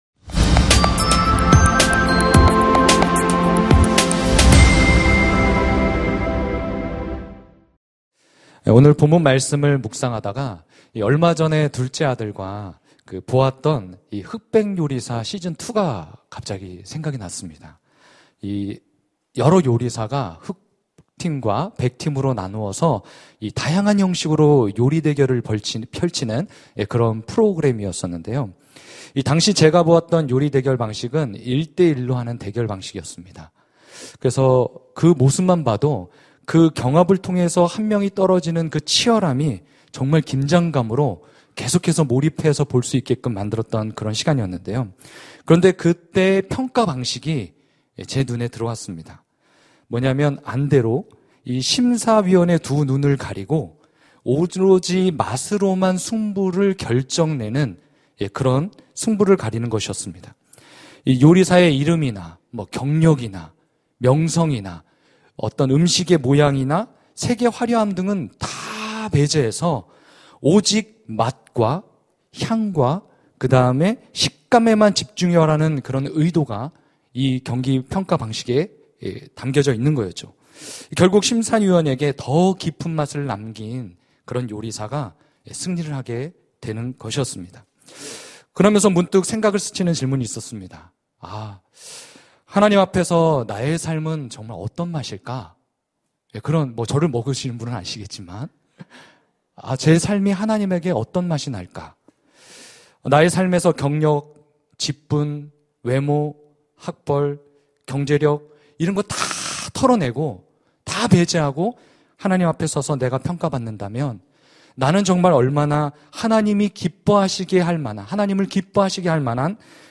설교 : 수요향수예배